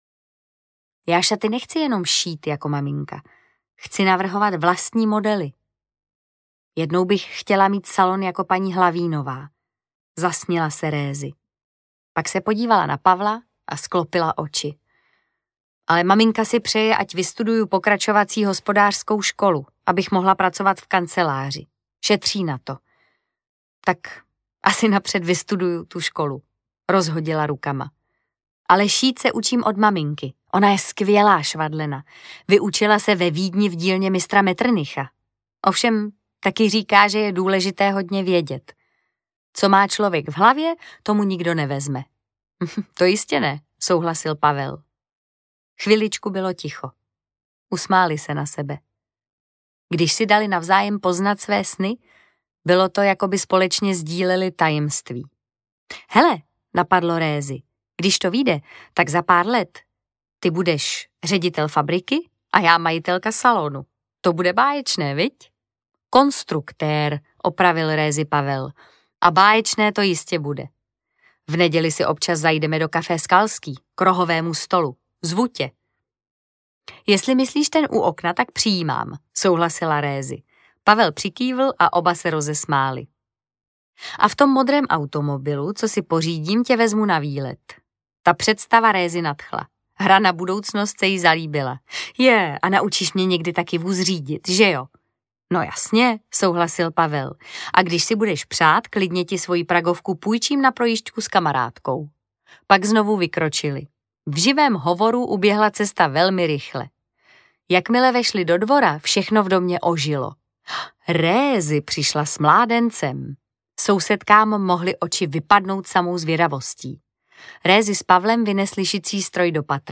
Labutí dům audiokniha
Ukázka z knihy
• InterpretMartha Issová